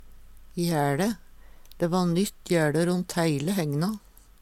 jæLe - Numedalsmål (en-US)